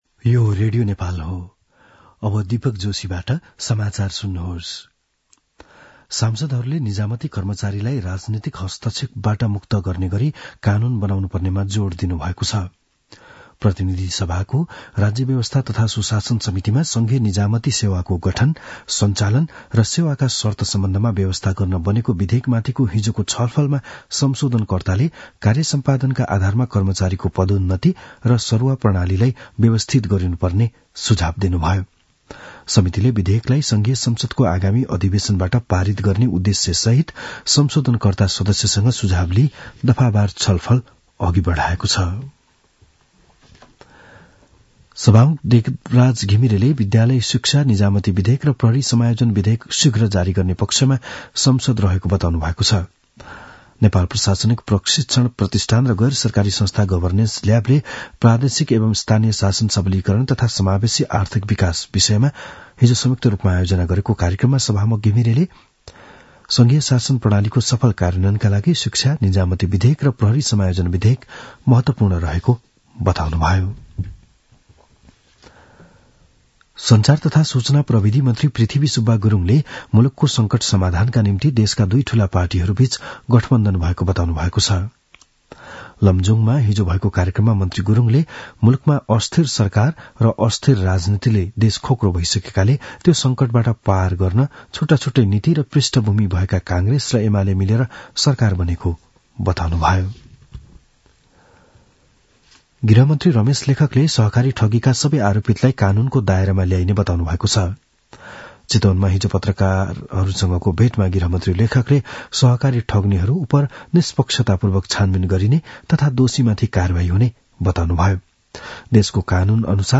बिहान ११ बजेको नेपाली समाचार : ६ पुष , २०८१
11-am-nepali-news-1-16.mp3